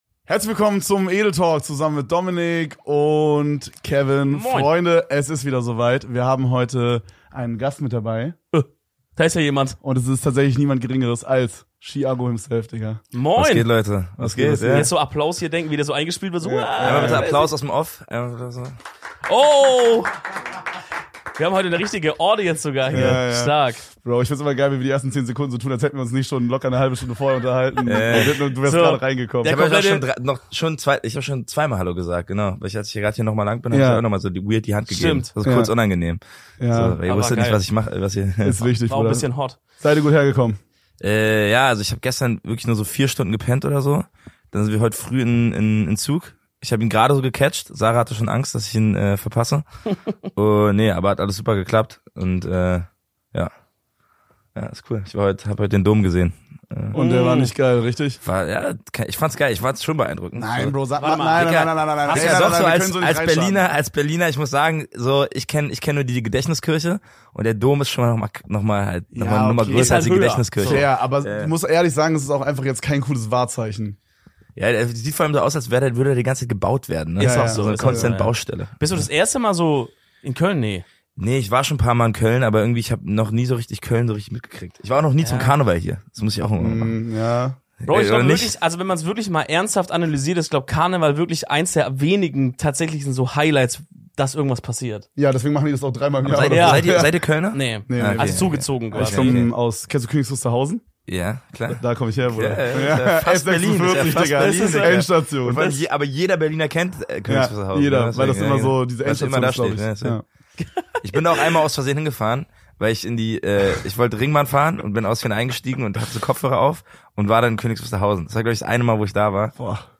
Heute haben wir bei uns im Studio Ski Aggu zu Gast.